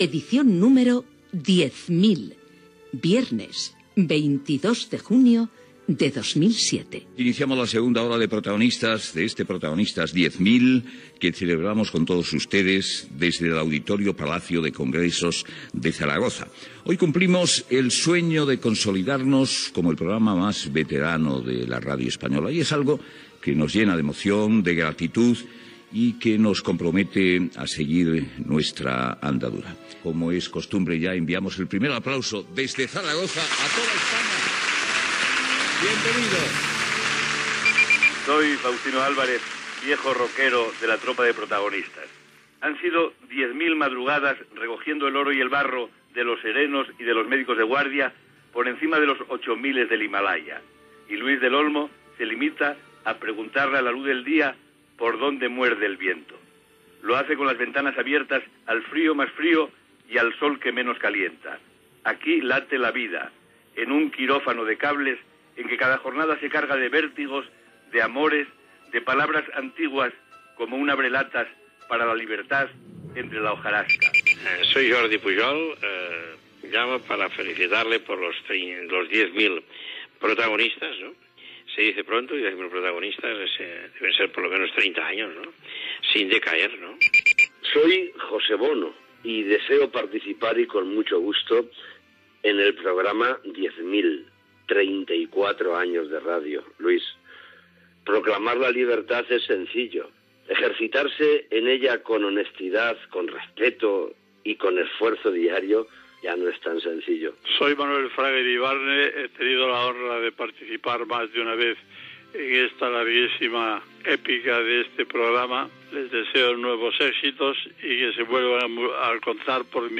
3658dce9f6192f10164fab8df8cca175f6ffaef1.mp3 Títol Punto Radio Emissora Punto Radio Barcelona Cadena Punto Radio Titularitat Privada estatal Nom programa Protagonistas Descripció Començament de l'edició 10.000 del programa des del Palau de Congressos de Saragossa.
Indicatiu del programa, intervencions telefòniques dels oients. Paraules dels periodistes Julia Otero i Iñaki Gabilondo.
Info-entreteniment